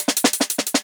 013_XOQ_Top_Fill_Shuffle_2.wav